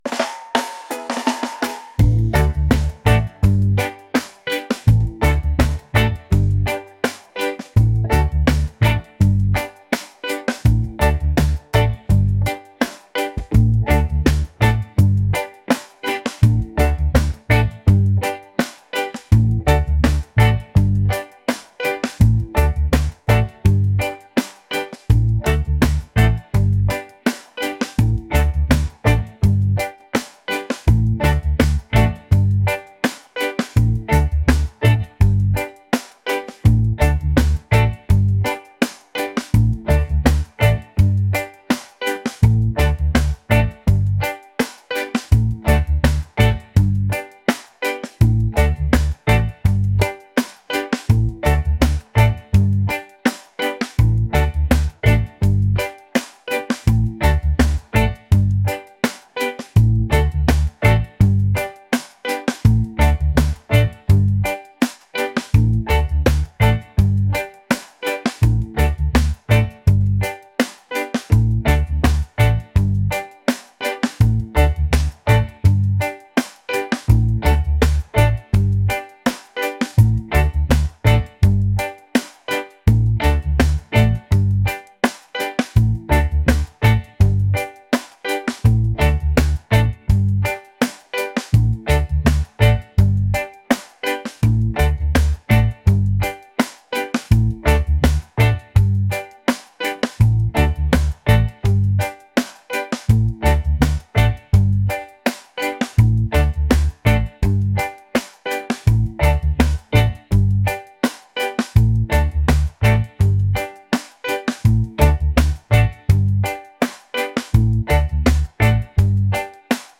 laid-back | reggae | positive